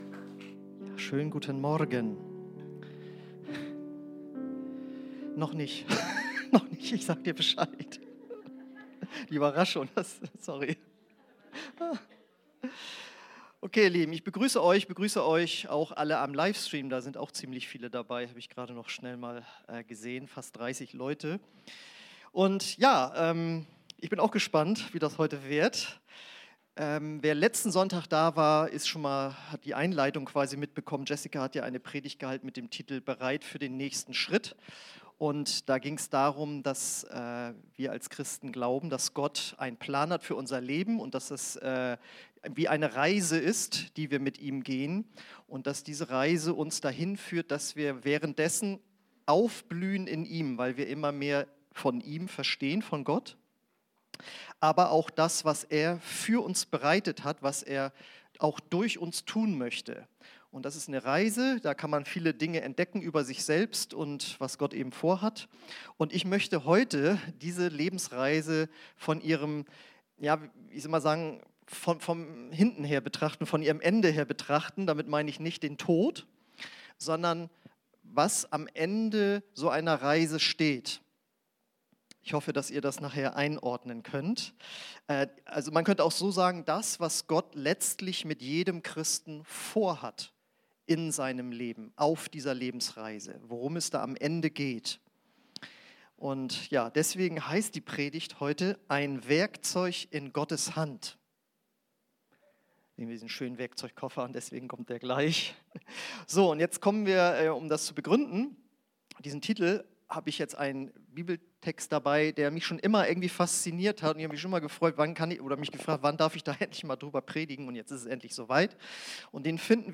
Predigten – OASIS Kirche